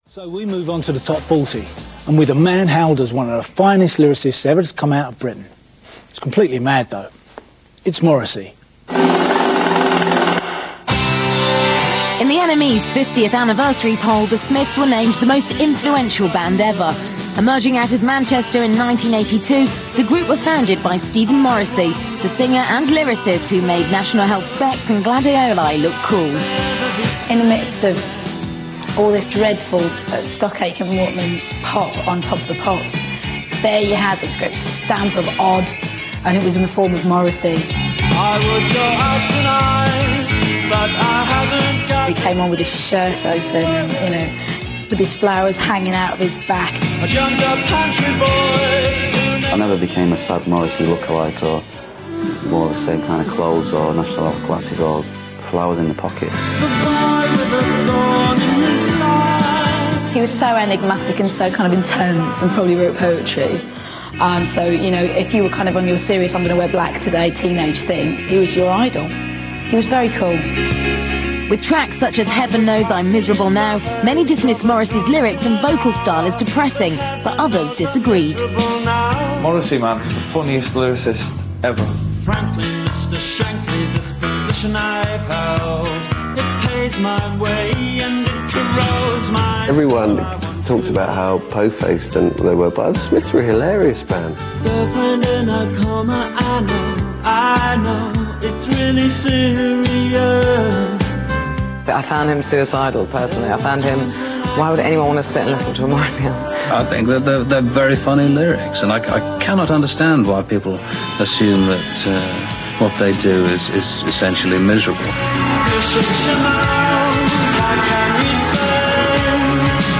The following audio file of the piece on Morrissey contains comments from the lovely Emma B (Radio 1), Badly Drawn Boy, Noel Gallagher, Dani Behr (BBC Childrens Presenter), John Peel and Clive Langer.